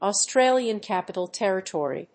Austrálian Cápital Térritory